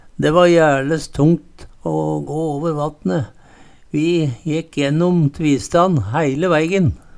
tvista - Numedalsmål (en-US)